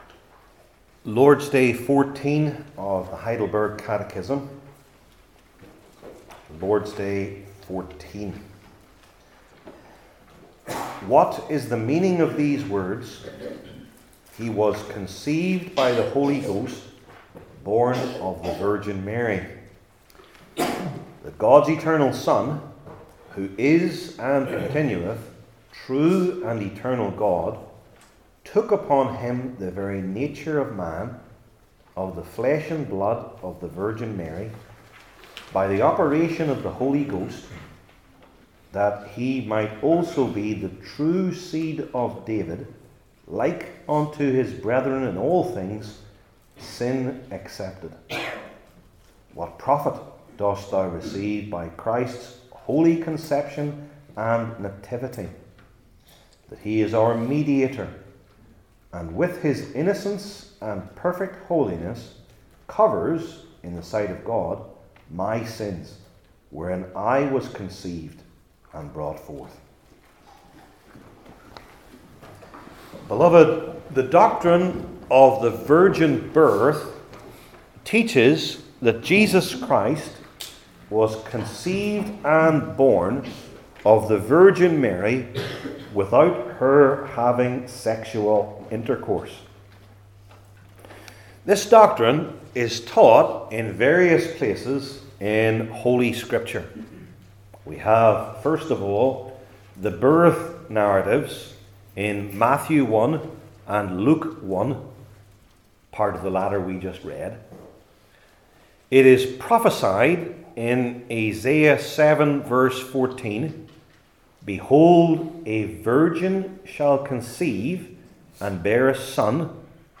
Heidelberg Catechism Sermons I. The Means II.